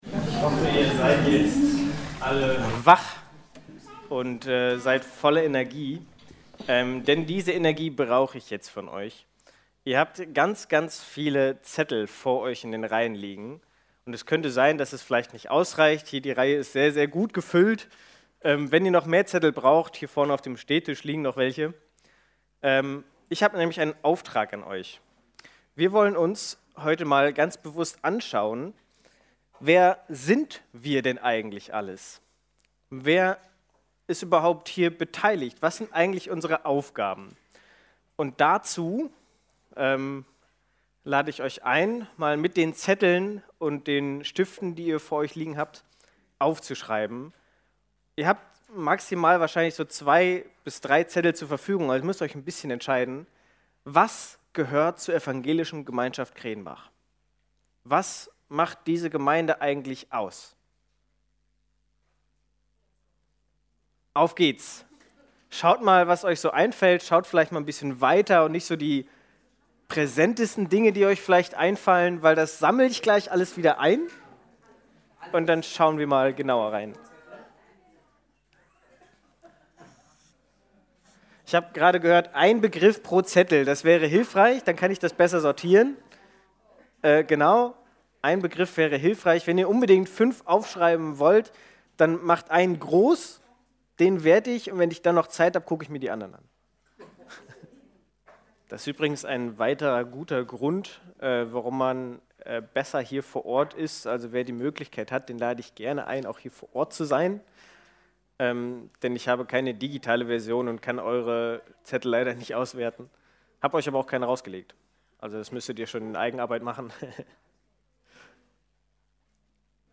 Sendungsgottesdienst
sendungsgottesdienst.mp3